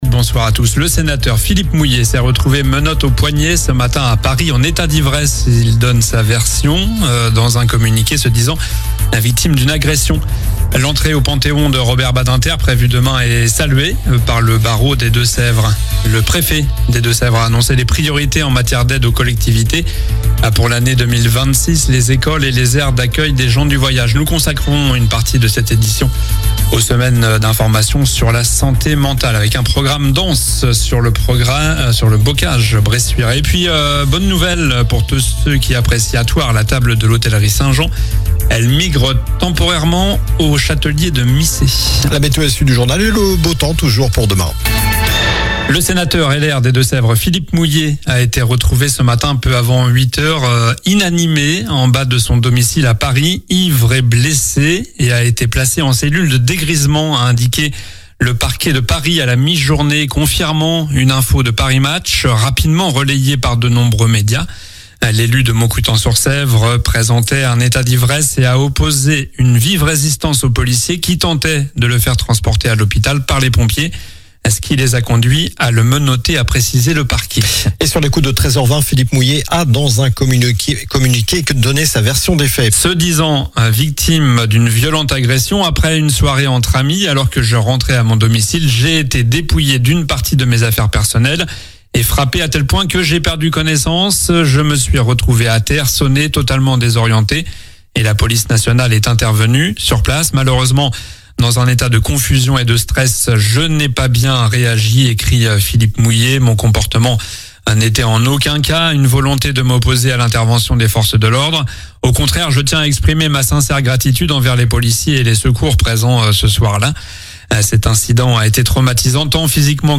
Journal du mercredi 8 octobre (soir)